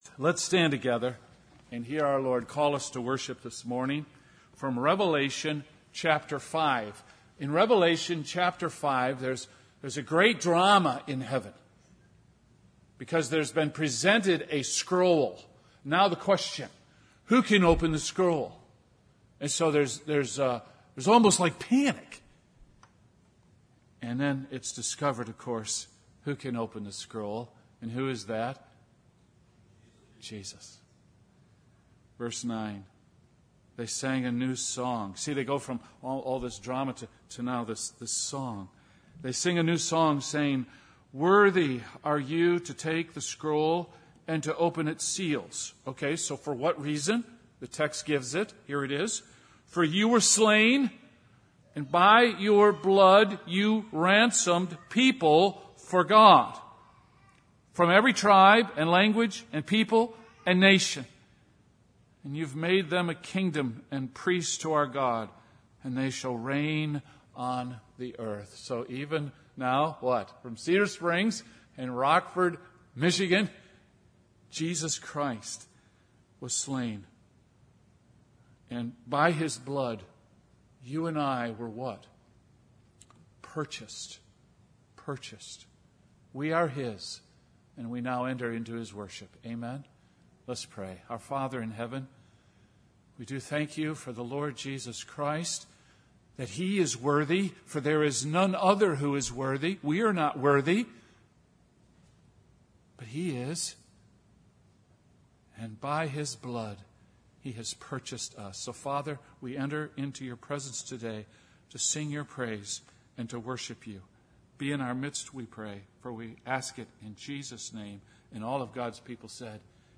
Sermons - Rockford Springs Orthodox Presbyterian - Page 9